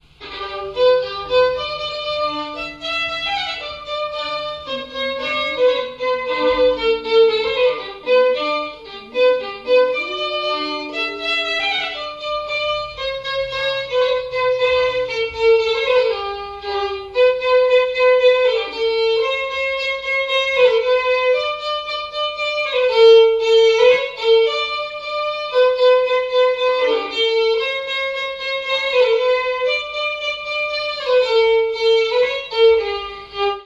Chants brefs - A danser
danse : polka
répertoire de bals et de noces
Pièce musicale inédite